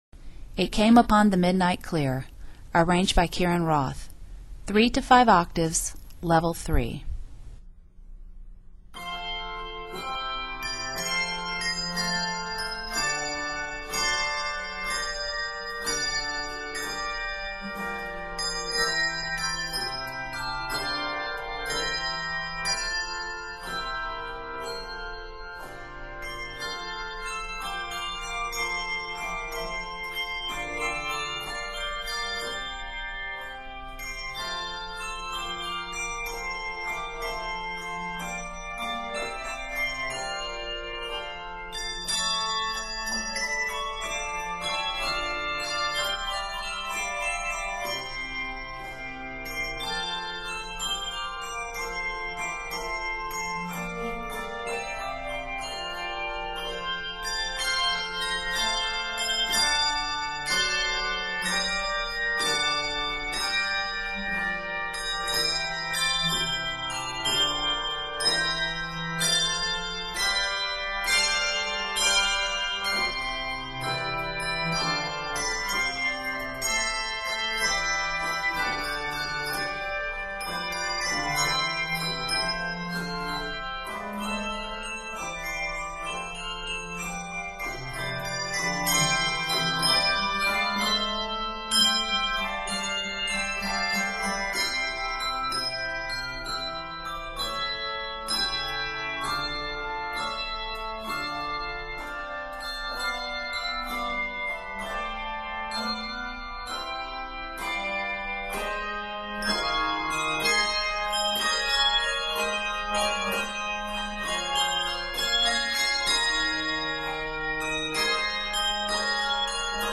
handbells